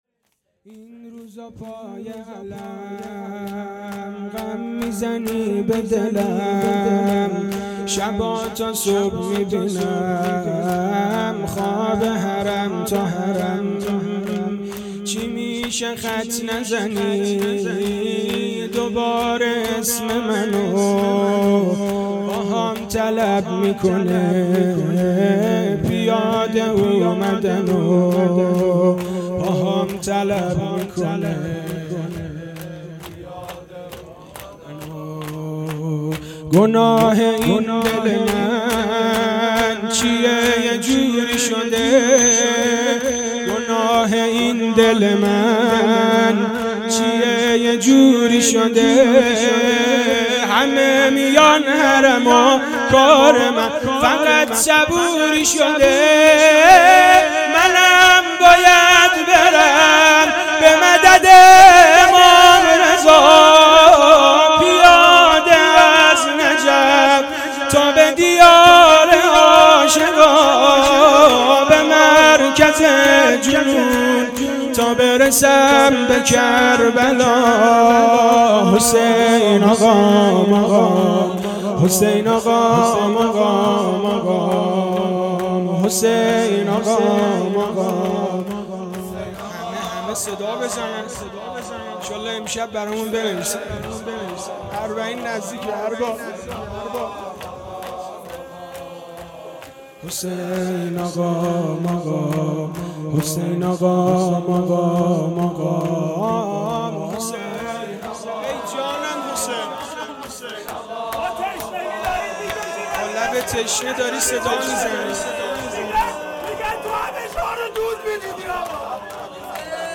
این روزا پای علم غم میزنی به دلم _ شور
شهادت حضرت امیرالمومنین امام علی علیه السلام